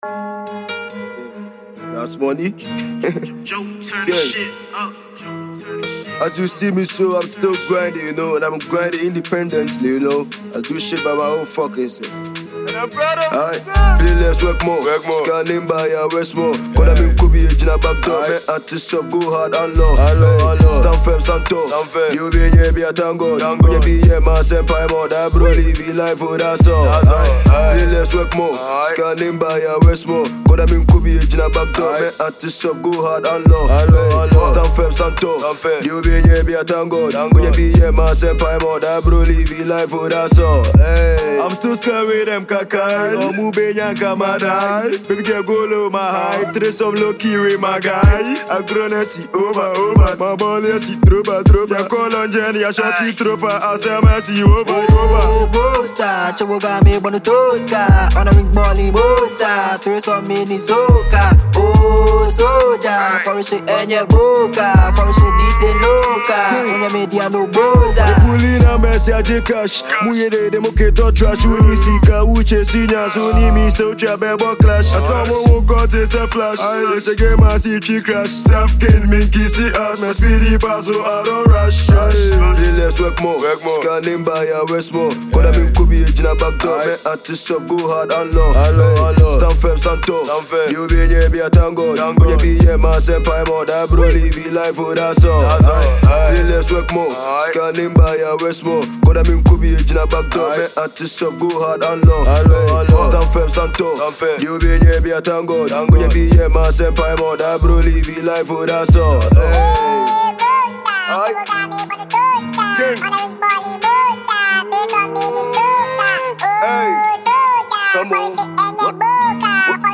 Ghana Music Music
Ghanaian rapper and songwriter
Christmas banger